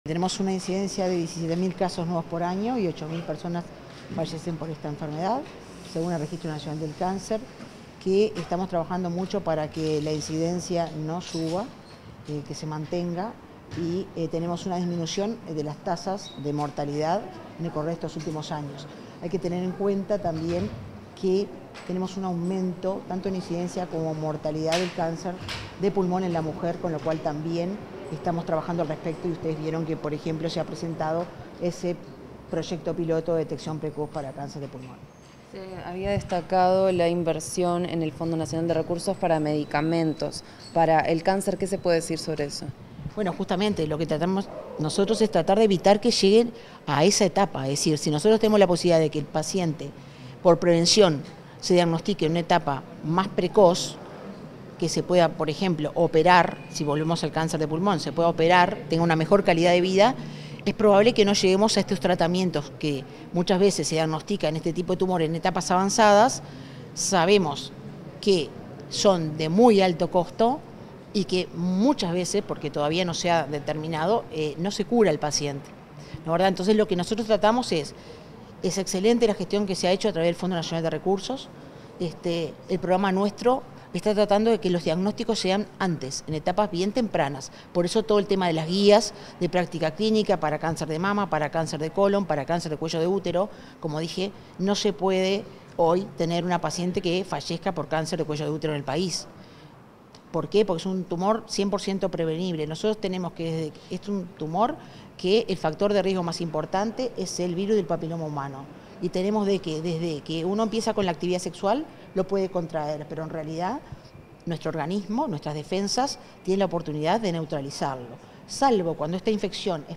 Declaraciones de la directora del Programa Nacional contra el Cáncer, Marisa Fazzino
Declaraciones de la directora del Programa Nacional contra el Cáncer, Marisa Fazzino 04/02/2025 Compartir Facebook X Copiar enlace WhatsApp LinkedIn Tras efectuar una presentación en el marco del Día Mundial contra el Cáncer, este 4 de febrero, la directora del Programa Nacional contra el Cáncer, Marisa Fazzino, realizó declaraciones a la prensa.